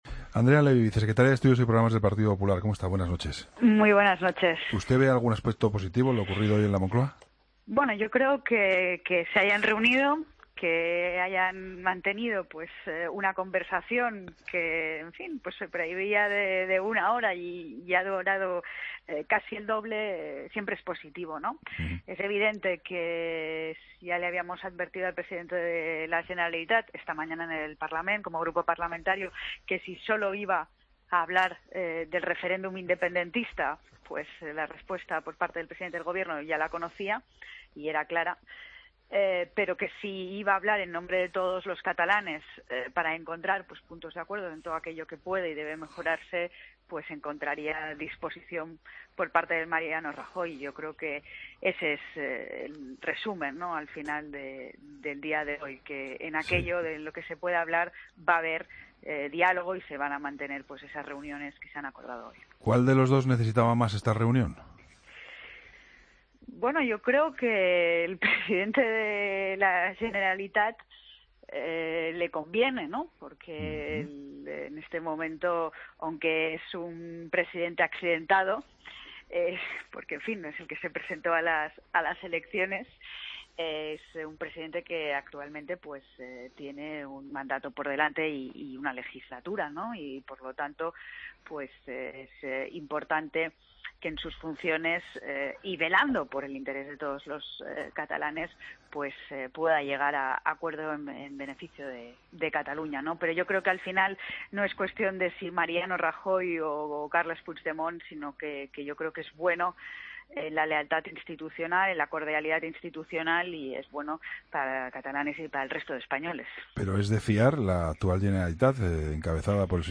AUDIO: Entrevista a la vicesecretaria de Estudios y Programas del PP, Andrea Levy, en 'La Linterna'